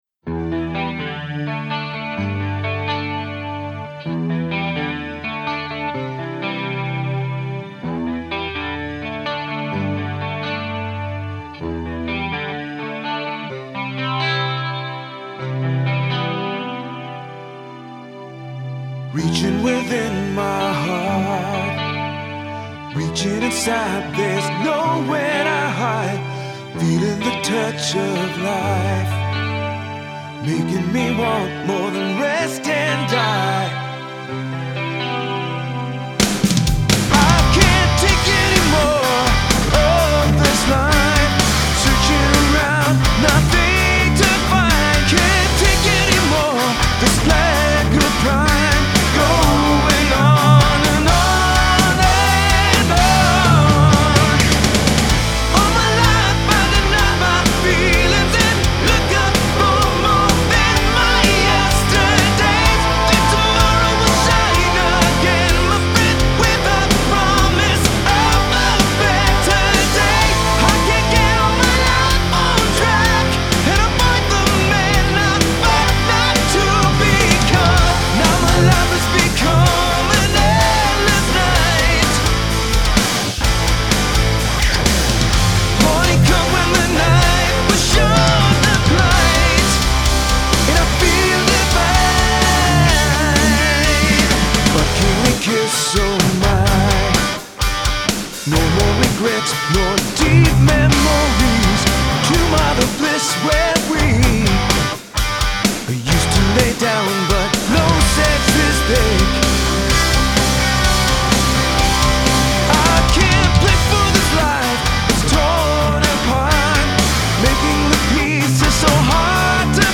Melodic Death/Black Metal Страна